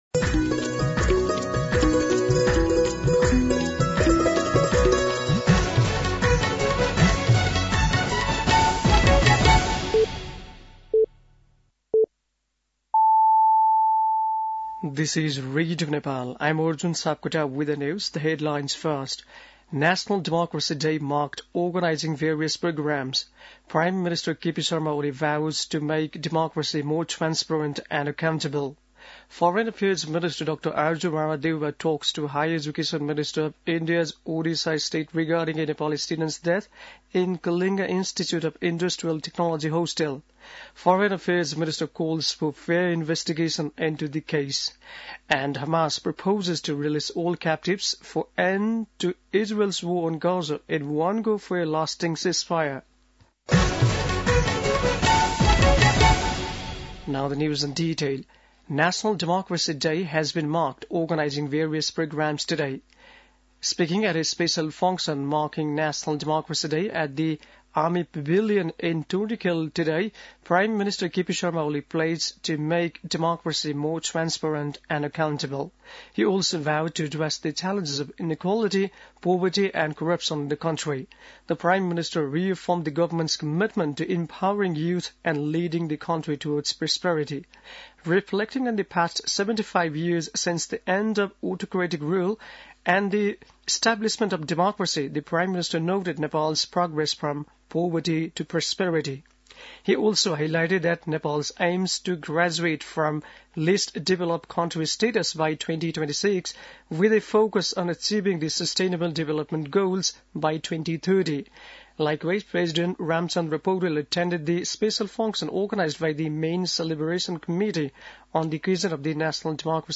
बेलुकी ८ बजेको अङ्ग्रेजी समाचार : ८ फागुन , २०८१